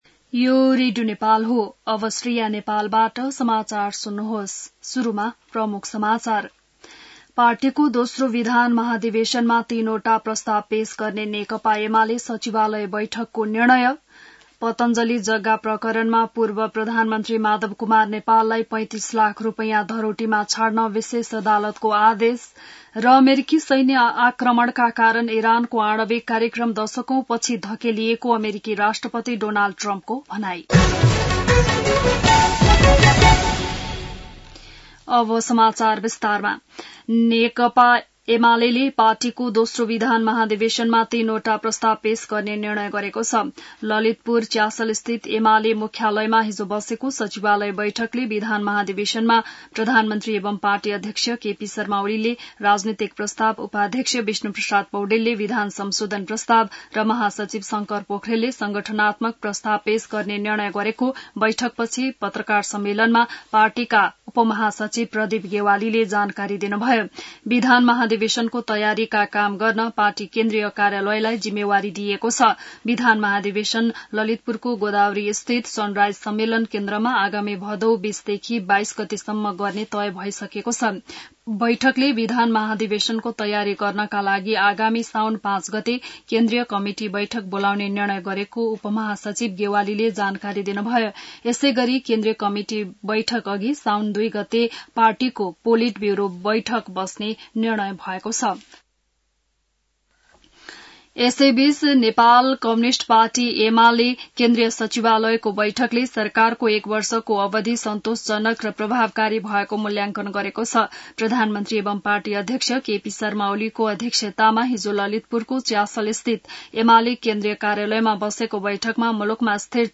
बिहान ९ बजेको नेपाली समाचार : १२ असार , २०८२